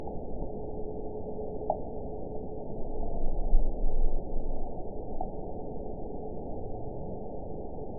event 917071 date 03/18/23 time 02:17:00 GMT (2 years, 1 month ago) score 8.83 location TSS-AB03 detected by nrw target species NRW annotations +NRW Spectrogram: Frequency (kHz) vs. Time (s) audio not available .wav